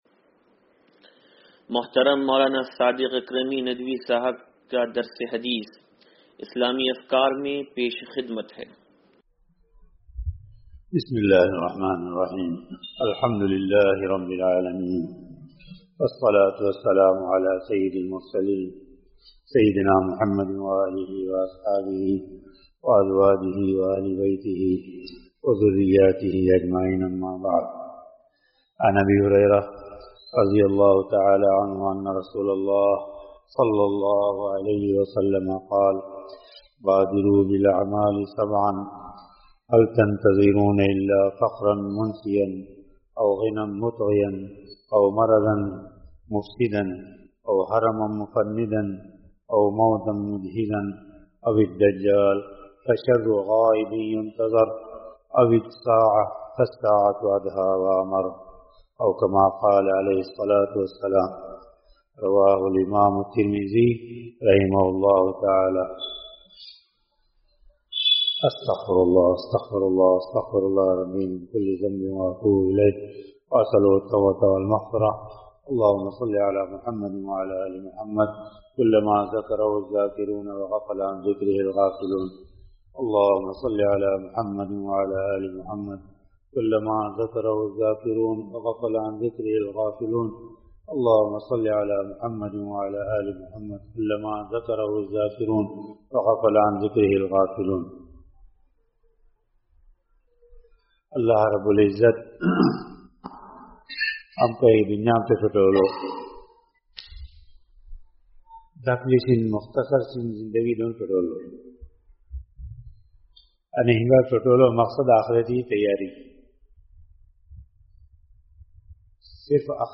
درس حدیث نمبر 0578
(سلطانی مسجد)